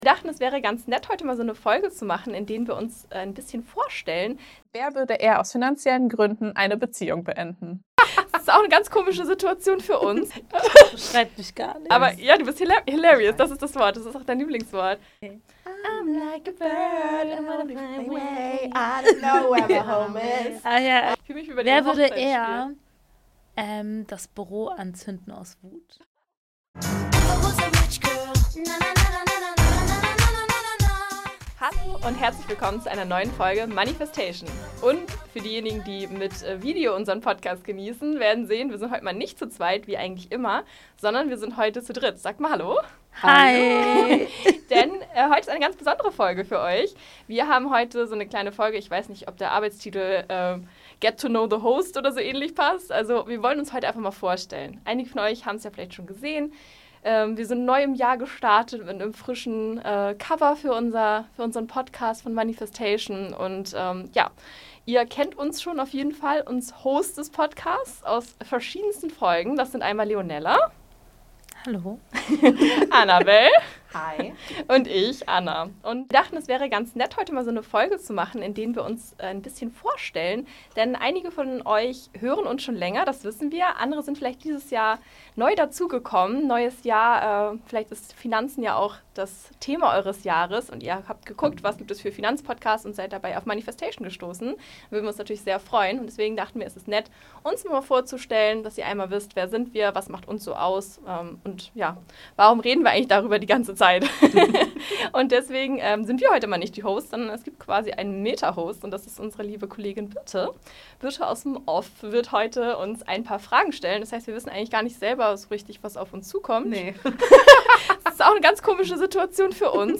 Die Folge ist locker, ehrlich und sehr unterhaltsam. Es wird viel gelacht, sich gegenseitig geneckt und zwischendurch auch über Geld gesprochen.